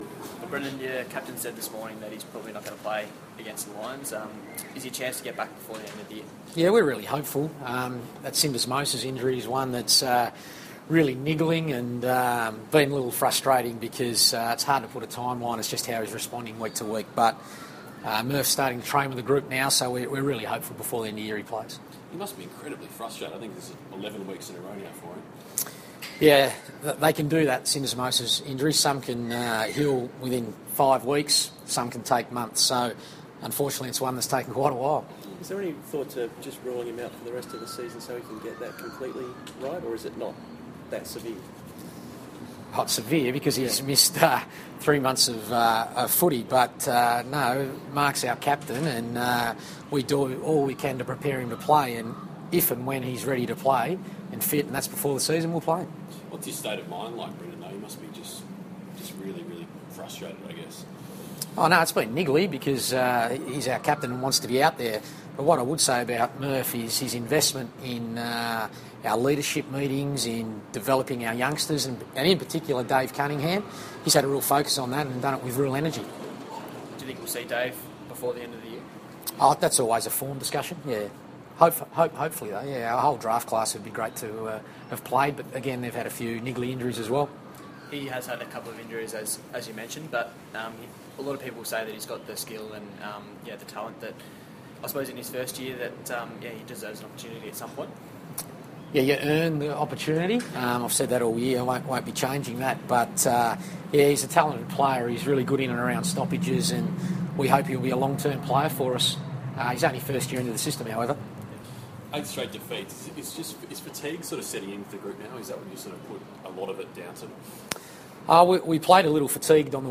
Brendon Bolton press conference - August 10
Carlton coach Brendon Bolton fronts the media at Ikon Park ahead of the Blues' trip north to face Brisbane.